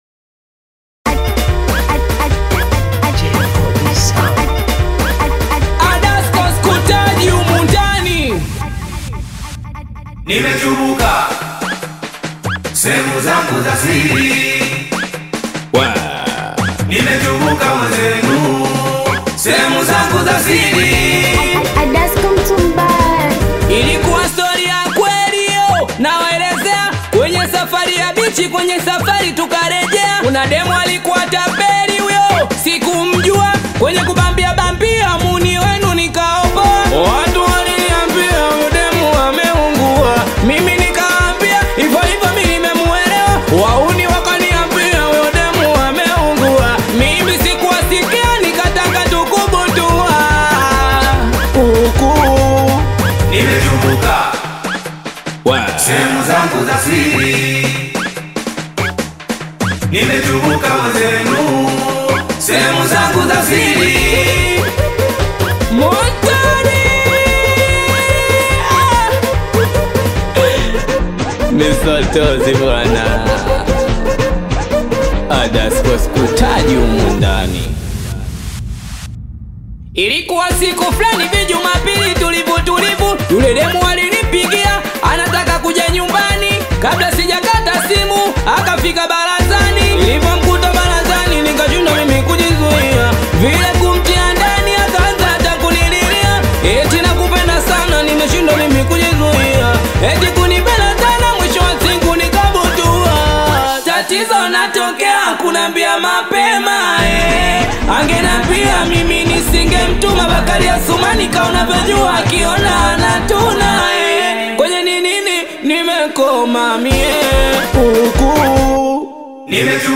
Tanzanian Bongo Flava Singeli
Singeli song